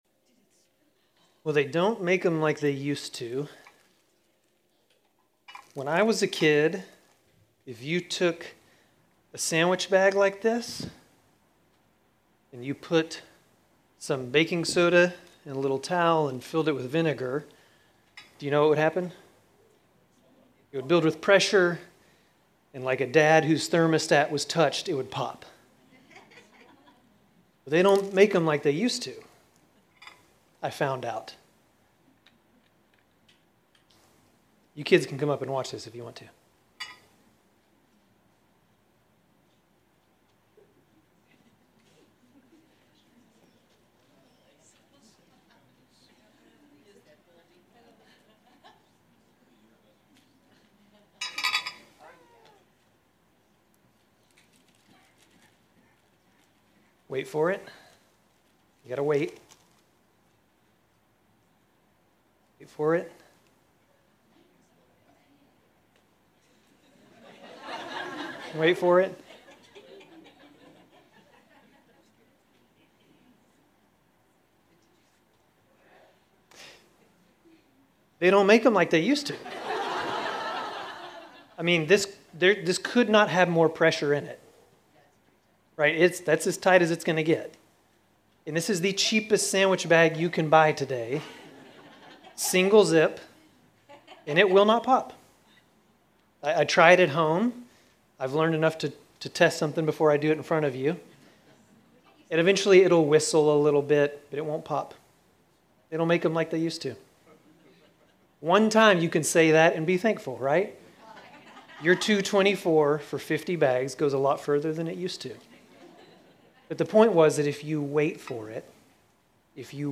Grace Community Church Dover Campus Sermons 6_29 Dover Campus Jul 01 2025 | 00:34:28 1x 00:00 / 00:34:28 Subscribe Share RSS Feed Share Link Embed